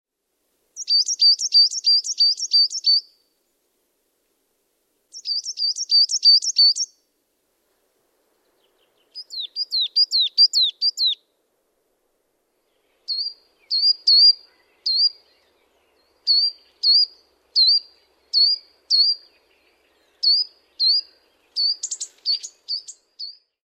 Kuusitiainen